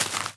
grass.1.ogg